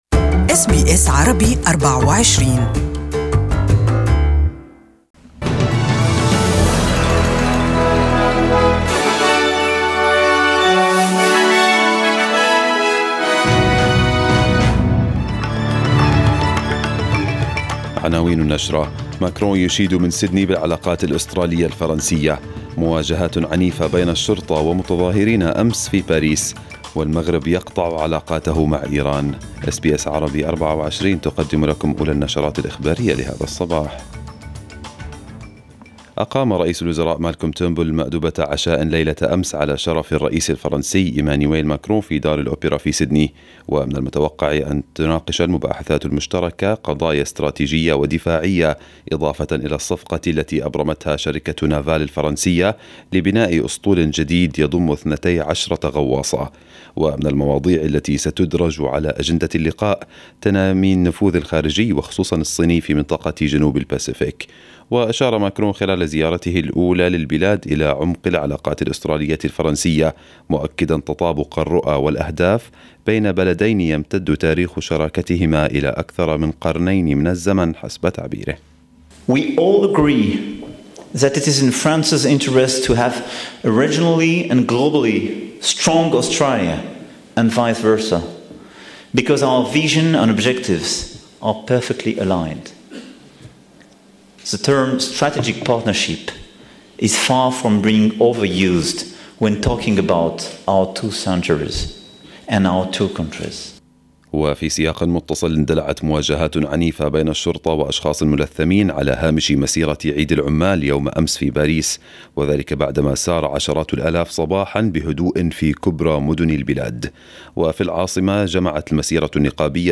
Arabic News Bulletin 02/05/2018